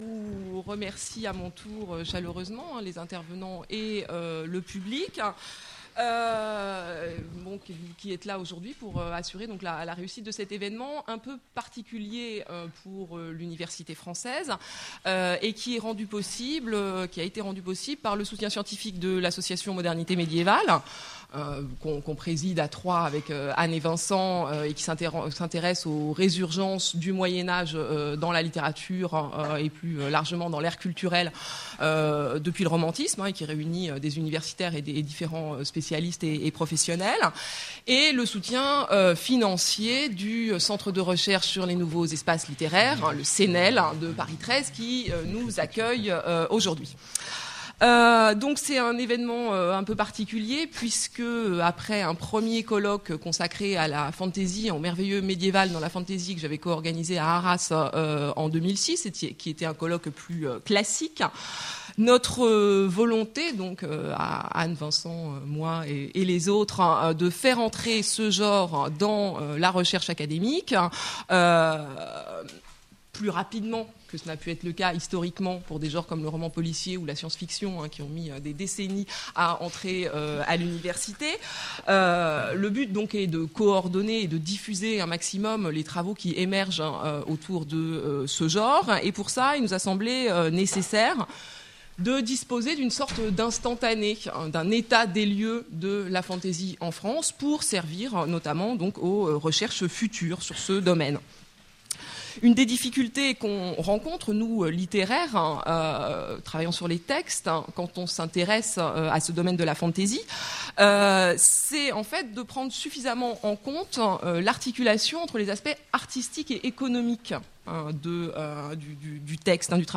table ronde